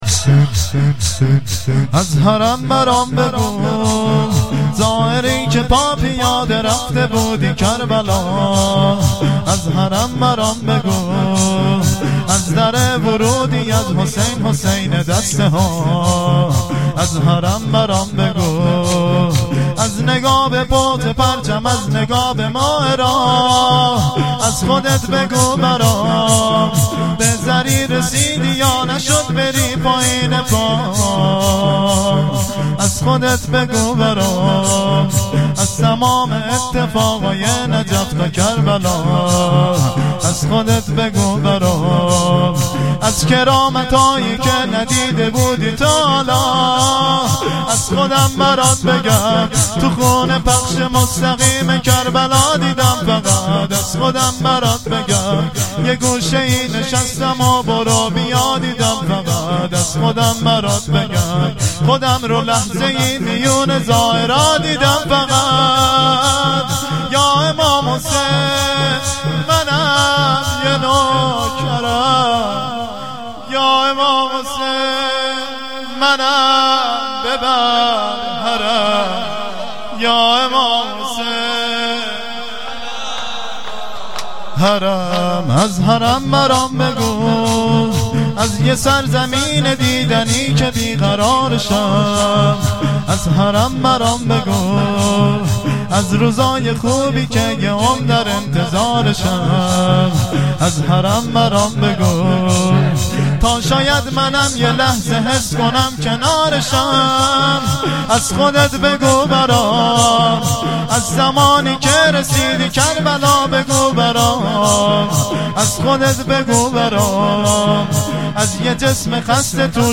مراسم بدرقه زائران اربعین